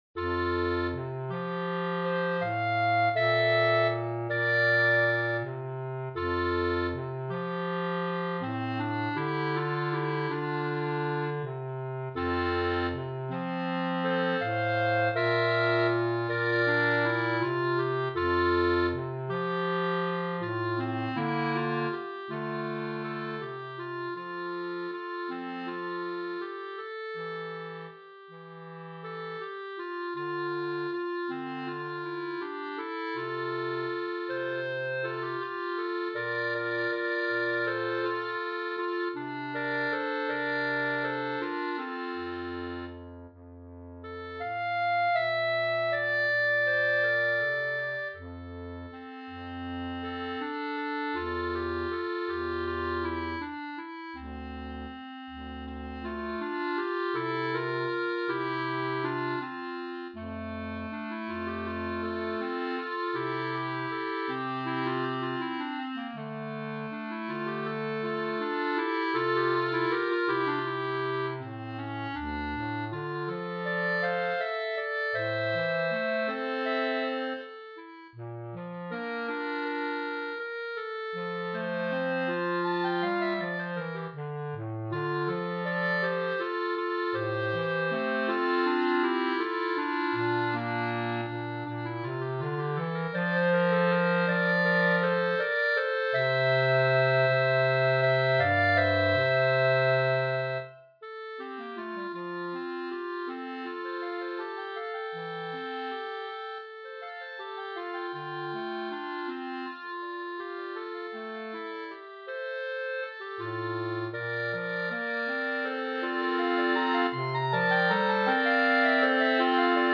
B♭ Clarinet 1 B♭ Clarinet 2 B♭ Clarinet 3 Bass Clarinet
单簧管四重奏
流行